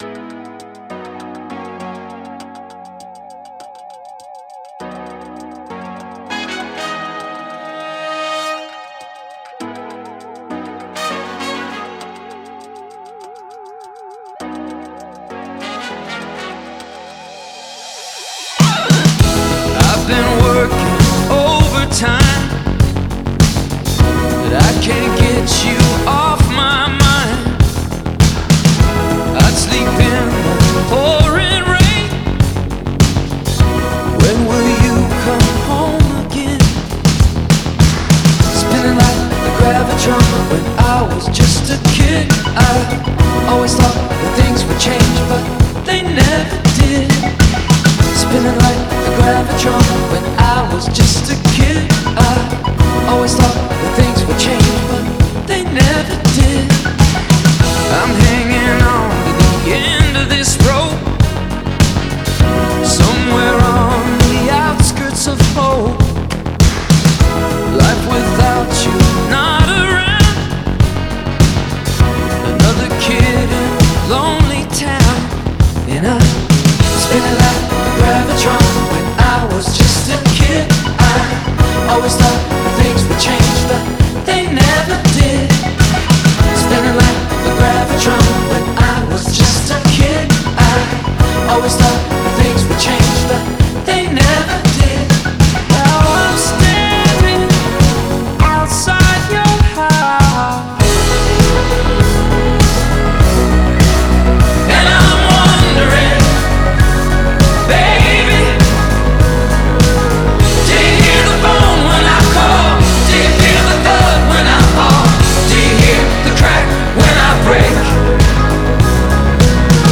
Genre: Pop Rock, Indie Rock